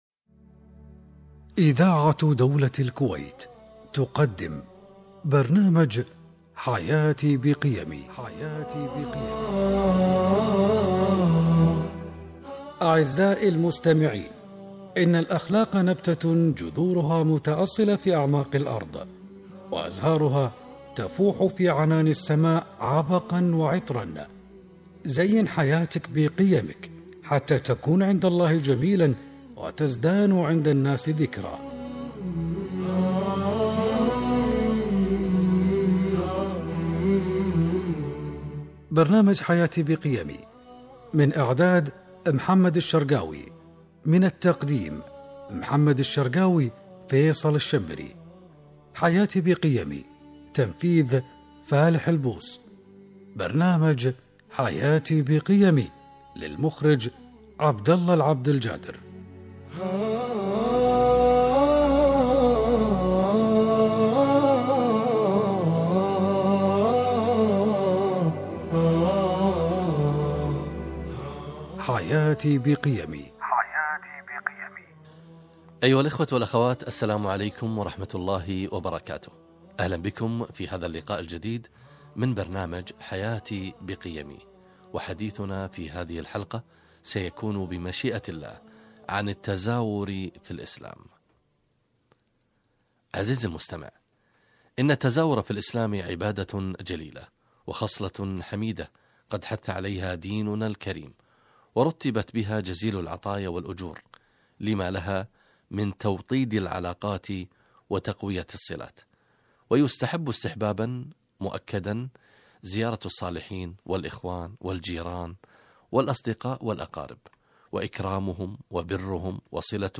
التزاور في الإسلام - لقاء إذاعي عبر برنامج قيمي حياتي